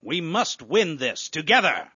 角色语音